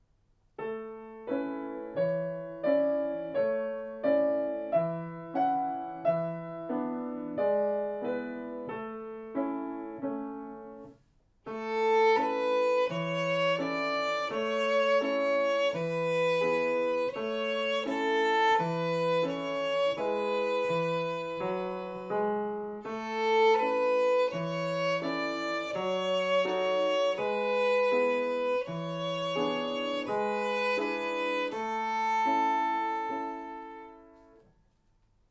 ご自宅での練習用に録音しました。